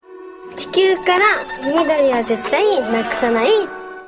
Di sini kita bisa mendengar senryu-senryu yang dibacakan.